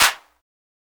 Clap (Feelings).wav